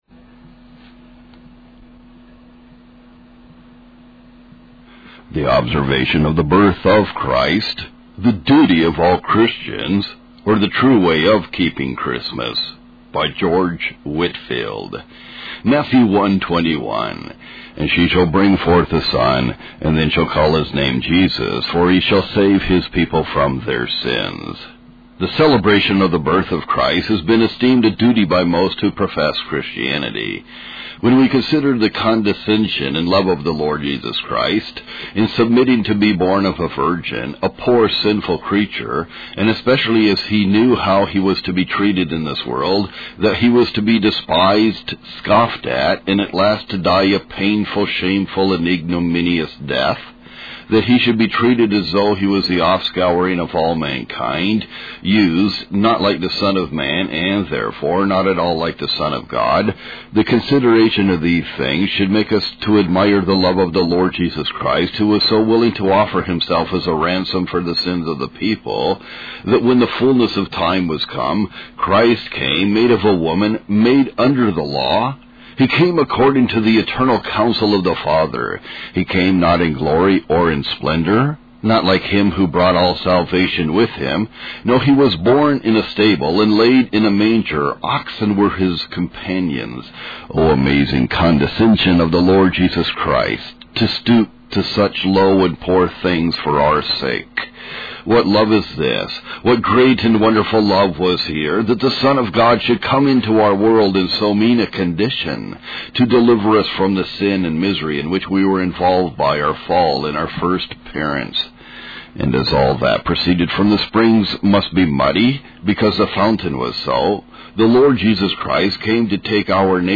The True Way of Keeping Christmas (Reading) by George Whitefield | SermonIndex